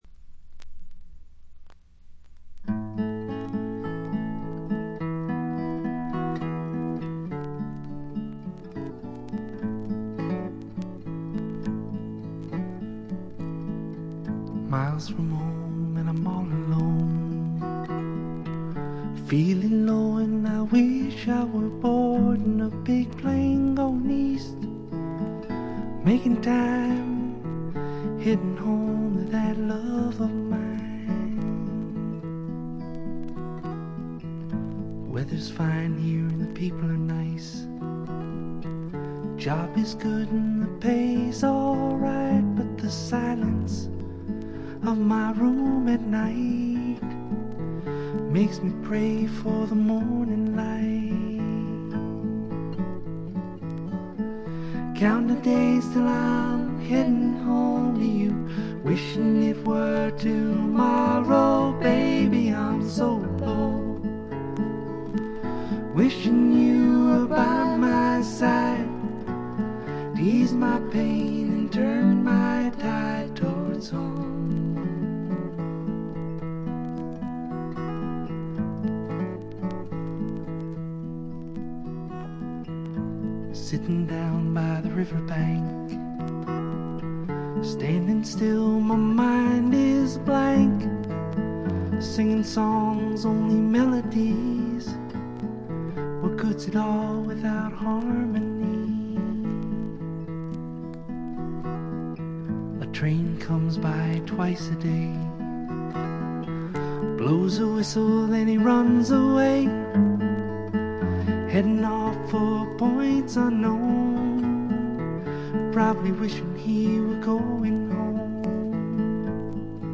バックグラウンドノイズ、チリプチやや多めですが鑑賞を妨げるようなものはありません。
試聴曲は現品からの取り込み音源です。